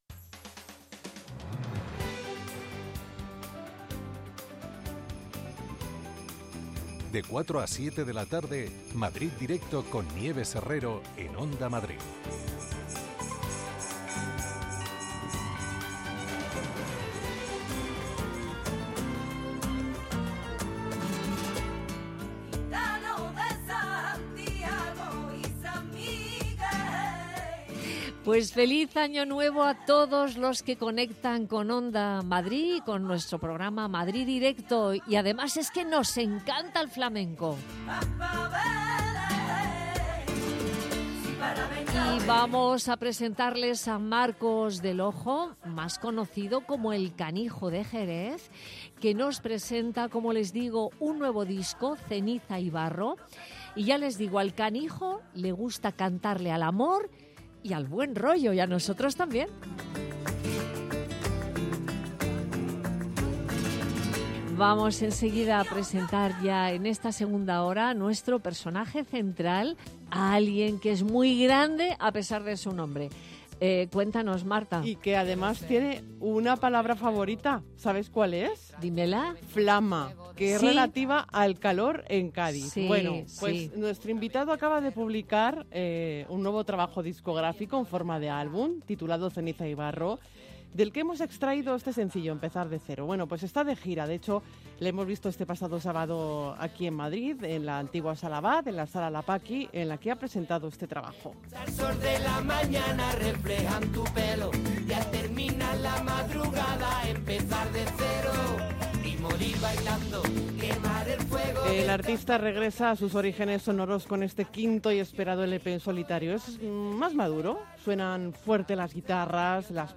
Nieves Herrero se pone al frente de un equipo de periodistas y colaboradores para tomarle el pulso a las tardes. Tres horas de radio donde todo tiene cabida: análisis de la actualidad, cultura, ciencia, economía... Te contamos todo lo que puede preocupar a los madrileños.